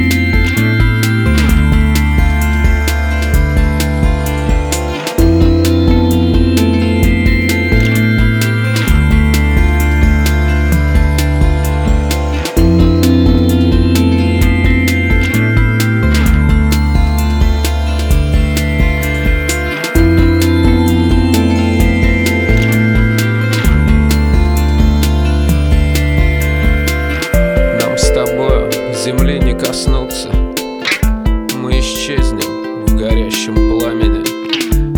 Alternative